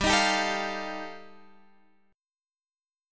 G7sus2#5 chord